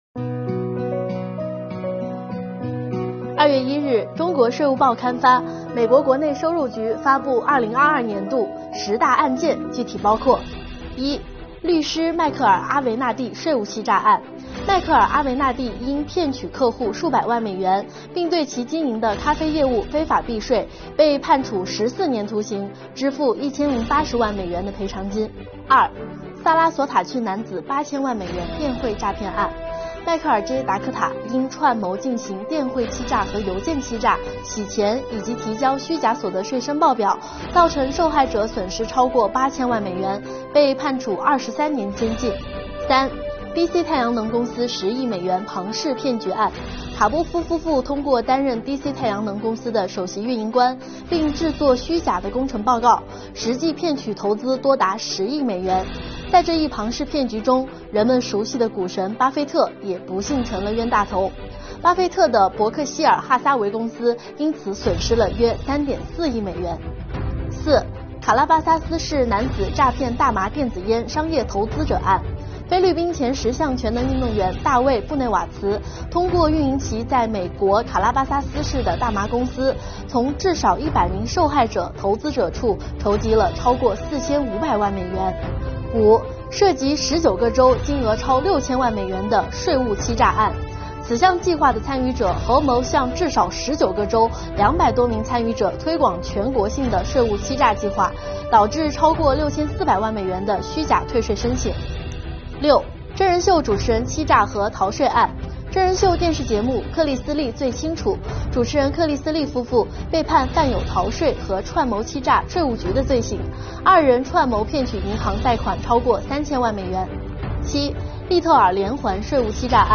今天起，中国税务报新媒体推出全新栏目《税收热报》，栏目采用视频播报的形式，为读者带来最新税收热点资讯，实现新闻的可听化、可视化、随身化和趣味化，欢迎收看。